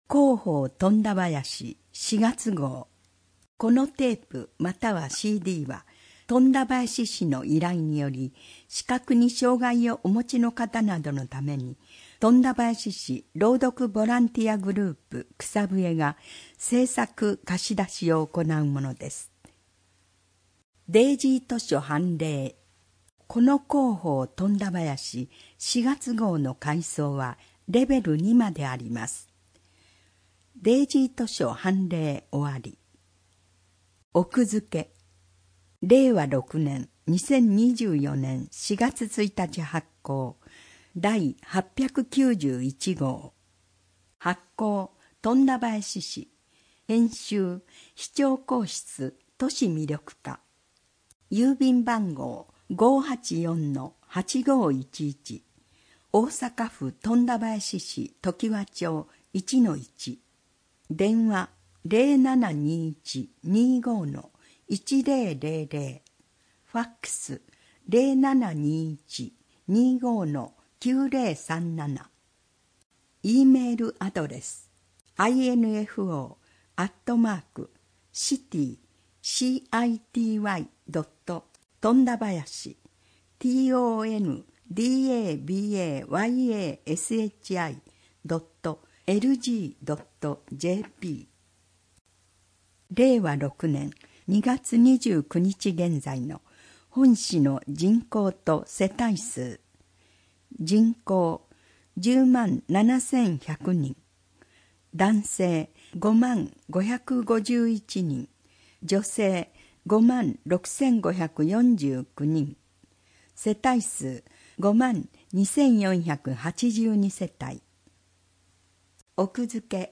この音声は、本市の依頼により富田林市朗読ボランティアグループ「くさぶえ」が視覚に障がいをお持ちの人などのために製作しているものです（図やイラストなど一部の情報を除く）。